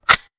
weapon_foley_pickup_12.wav